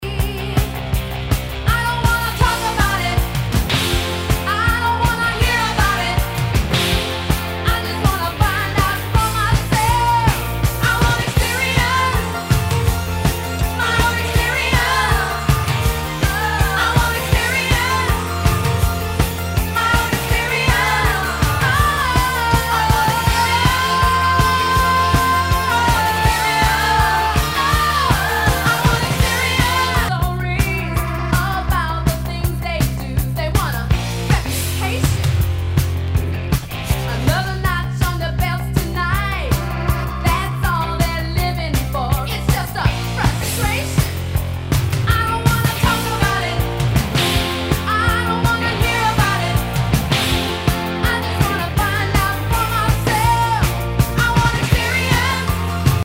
ROCK/POPS/INDIE
ナイス！シンセ・ポップ！